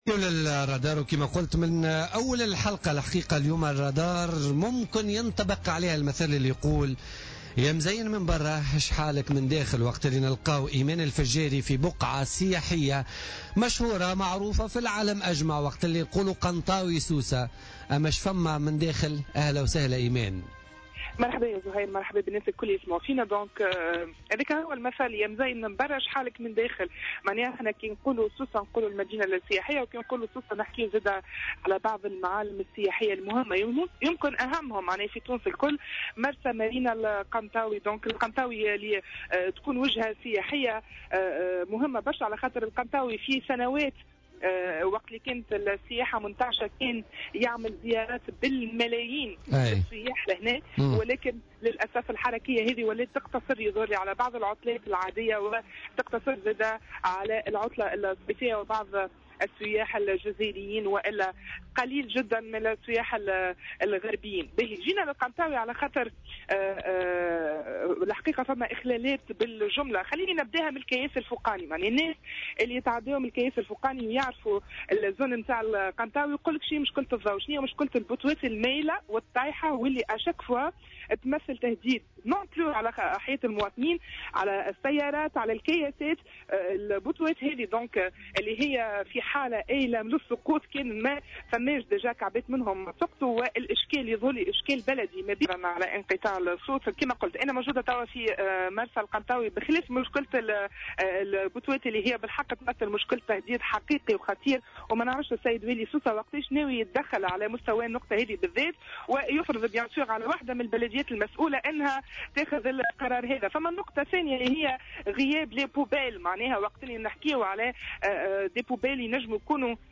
حلّ فريق فقرة "الرادار" اليوم الأربعاء بمرسى القنطاوي، أين عاين جملة من الاخلالات من ذلك الوضعية الكارثية للطرقات و وجود أعمدة كهربائية آيلة للسقوط، إضافة إلى غياب الحاويات المخصصة للفضلات.